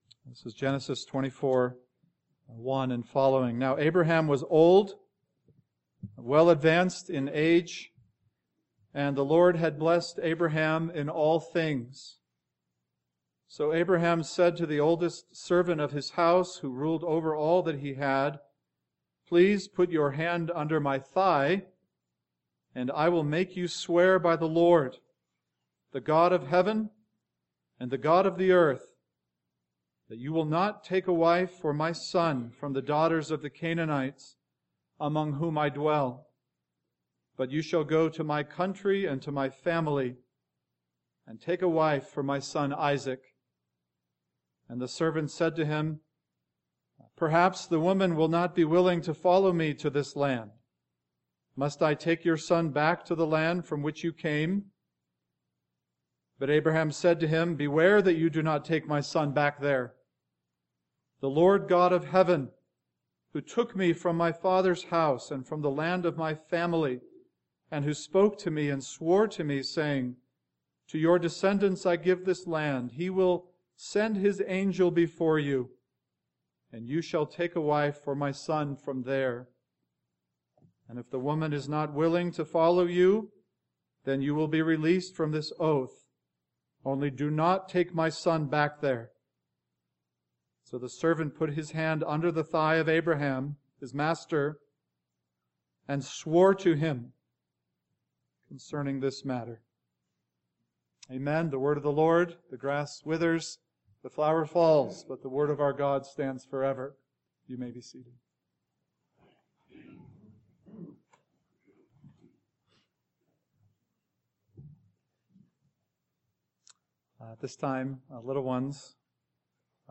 AM Sermon